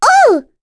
Rephy-Vox_Damage_03.wav